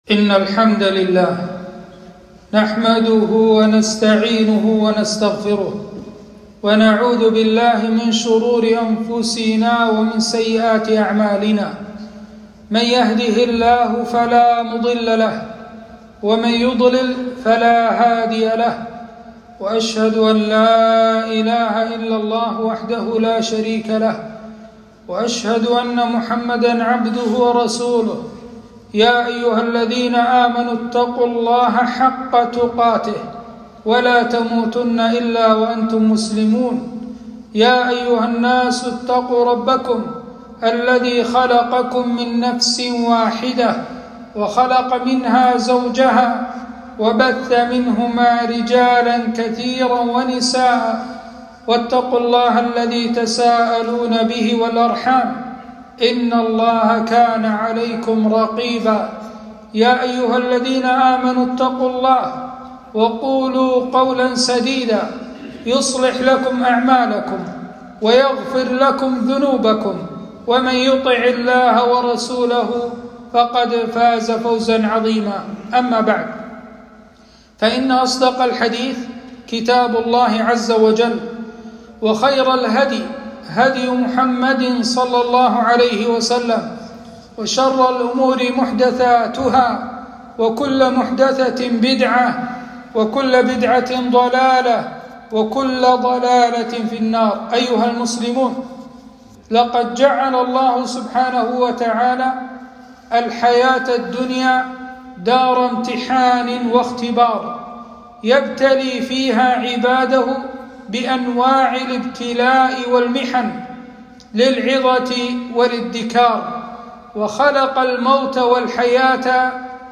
خطبة - الأمراض والأوبئة إعذار وإنذار